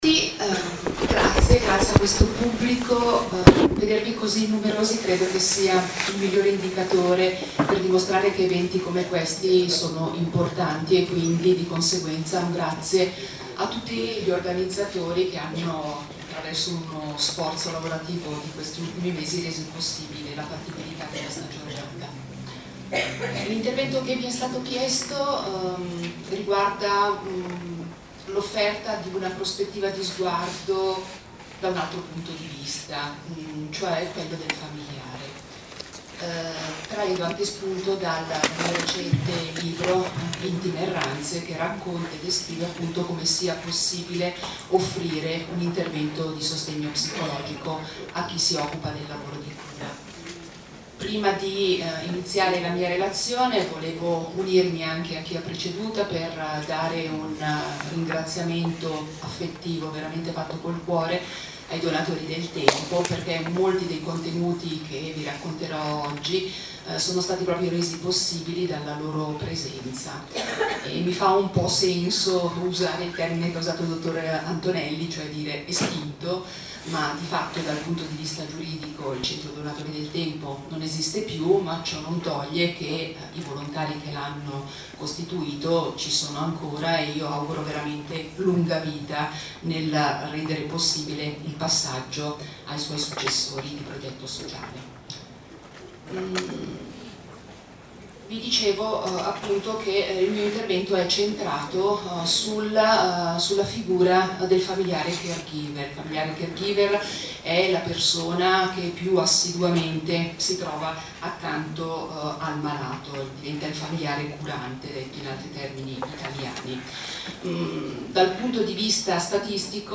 AUDIO DELLA LEZIONE
Incontro di formazione del 16 ottobre 2013, a cura del Piano di Zona di Como